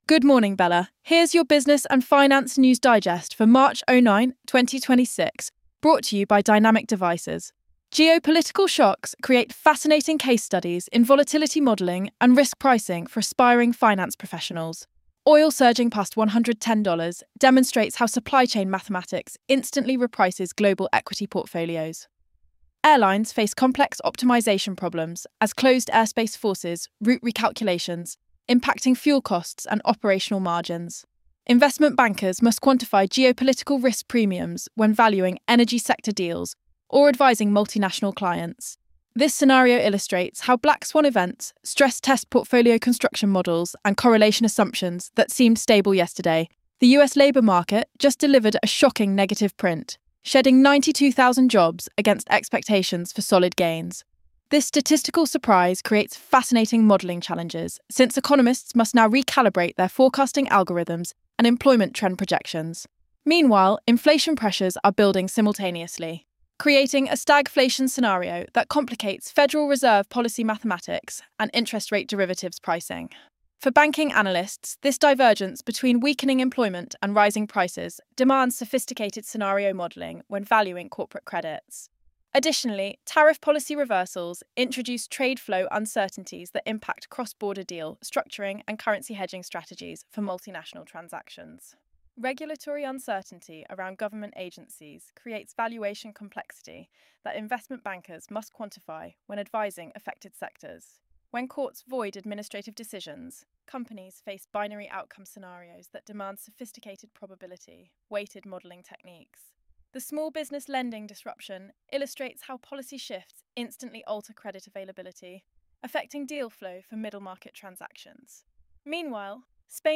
Daily AI-generated business and finance briefing for April 12, 2026 covering investment banking, VC, markets, and strategic insights.
Professional Irish voice delivers daily UK news digest.